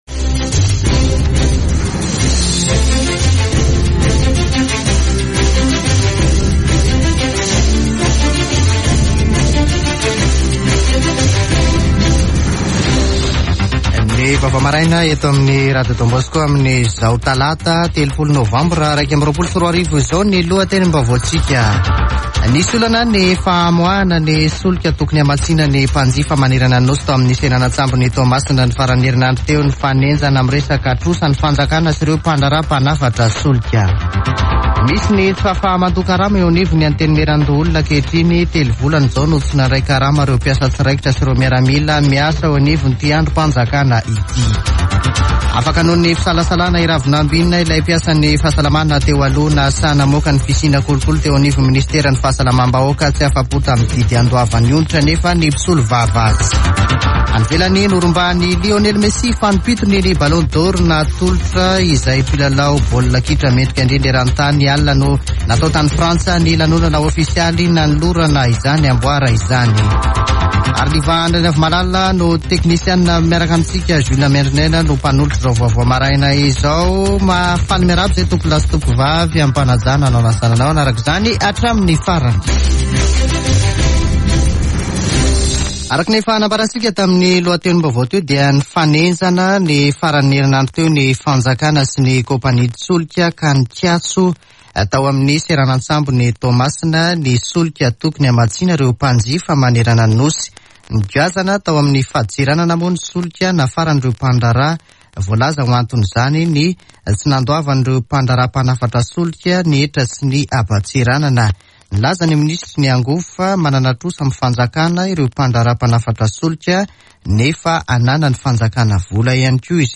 [Vaovao maraina] Talata 30 novambra 2021